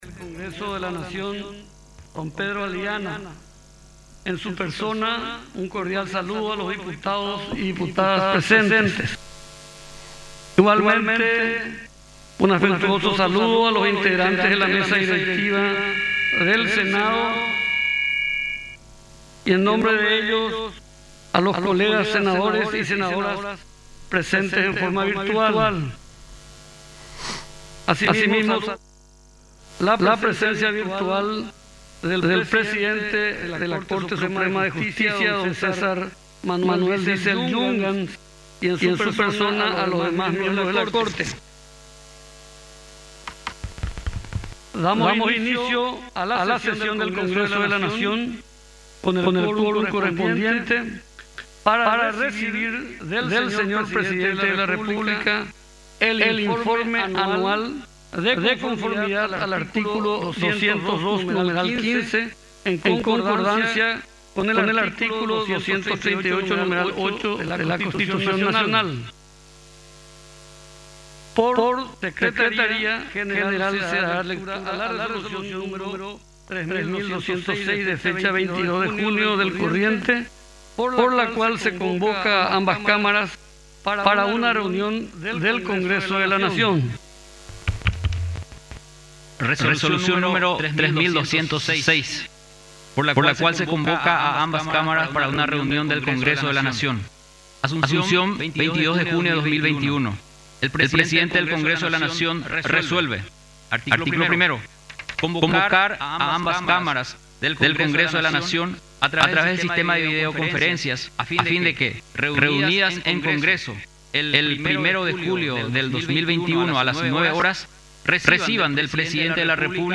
El presidente de la República, Mario Abdo Benítez, resaltó los números macroeconómicos en su informe de gestión anual realizado este jueves. Destacó que Paraguay está entre los países menos golpeados económicamente en la región.
15-INFORME-DE-GESTION-MARIO-ABDO.mp3